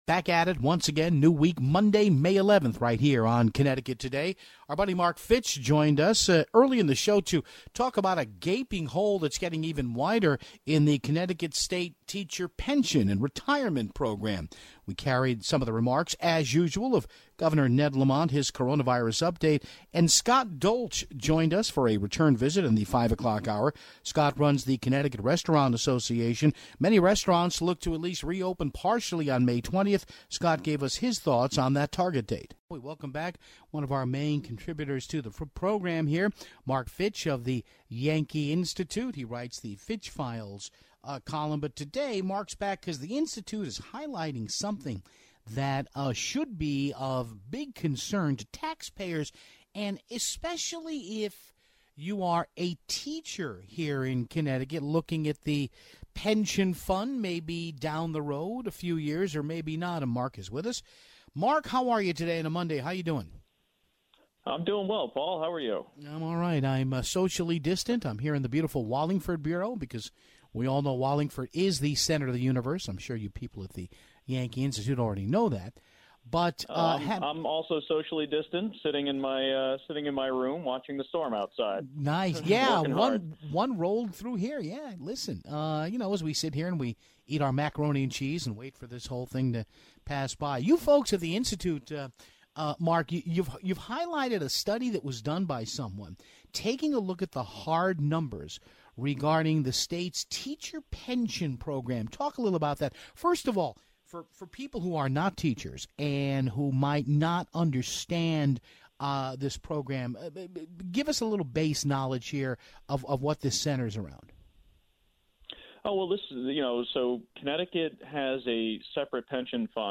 We had portions of Governor Lamont's daily press update for you.